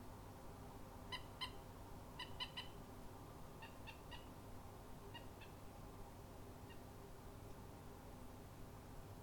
Pita de auga
Gallinula chloropus
Canto